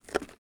box_get.ogg